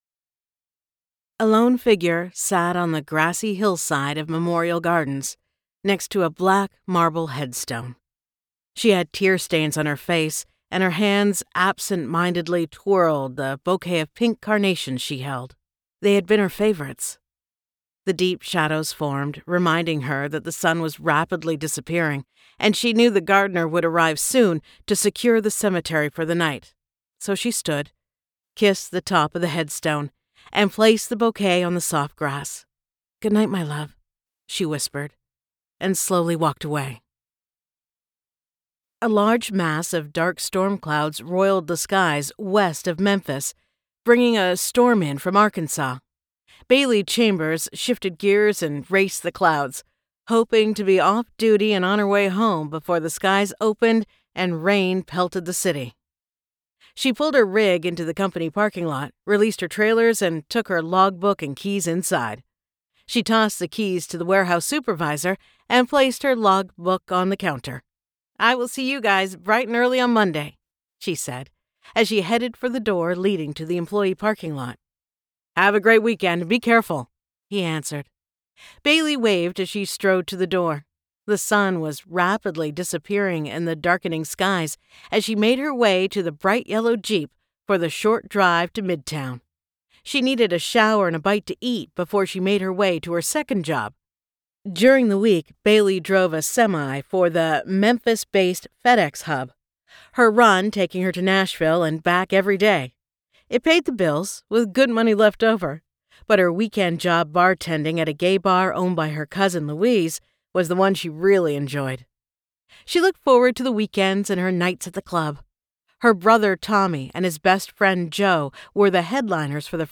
[Audiobook]